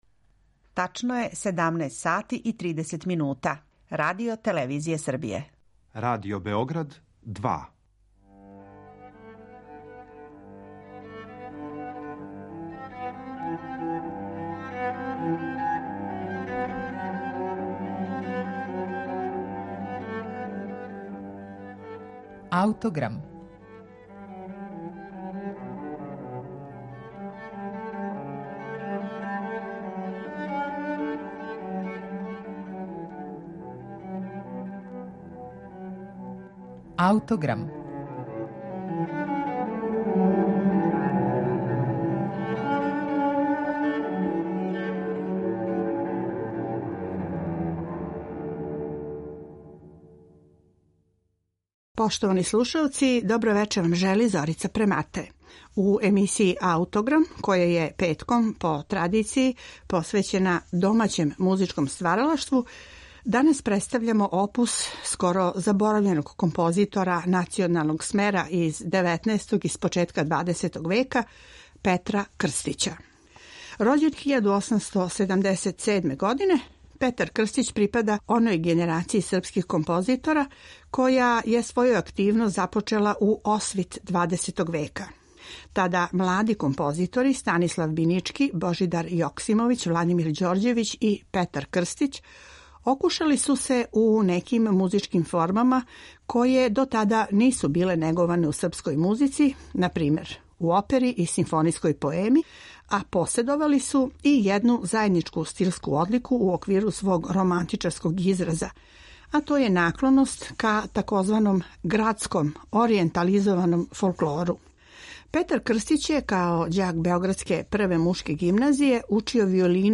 Данас ћете имати прилику да чујете како су звучала дела Петра Крстића, композитора активног током првих деценија прошлог века, присталице раног романтизма и грађанске музике бидермајера. Вече ће започети његова увертира „Косовска трагедија" из 1912. године. На нашем архивском снимку слушате Симфонијски оркестар Радио-телевизије Београд